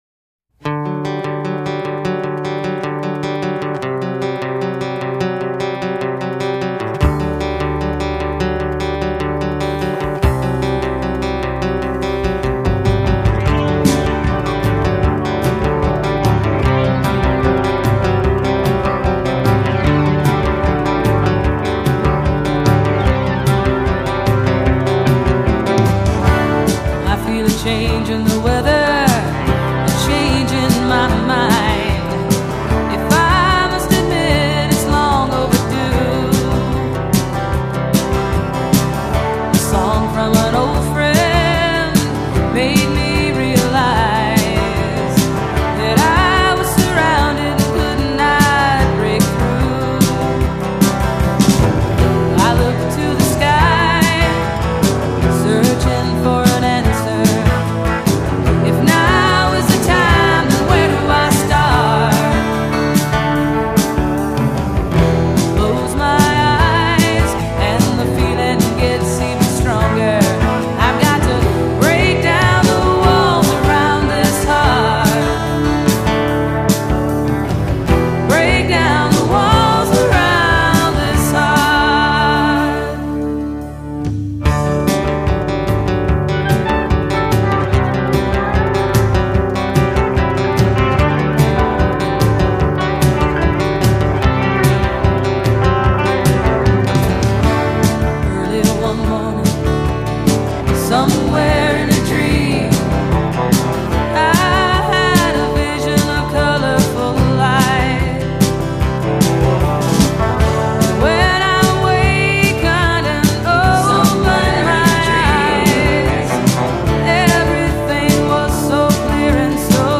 vocals, guitar
Drums